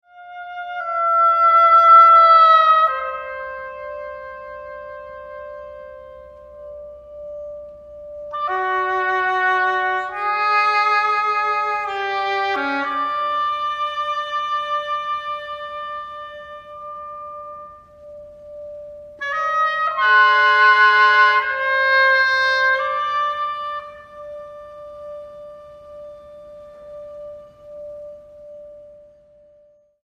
oboe
oboe y copas de cristal